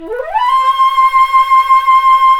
FLT FLT FX 3.wav